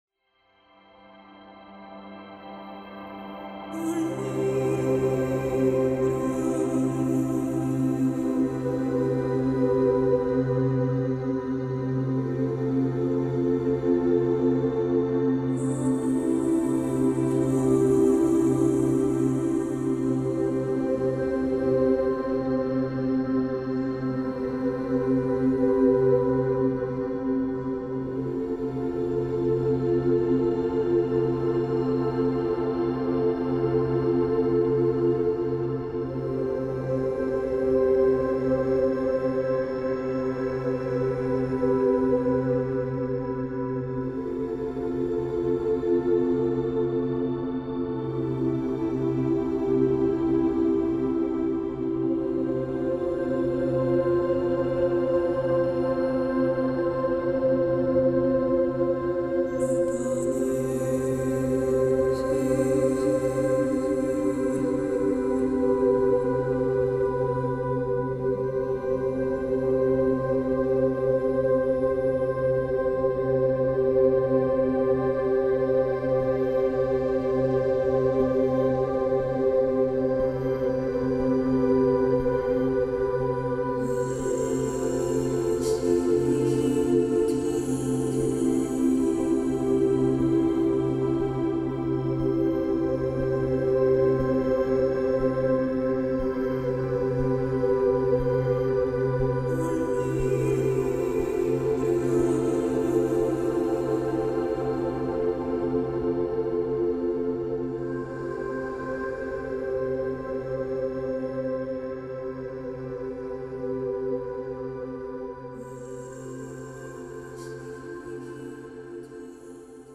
heart opening melodies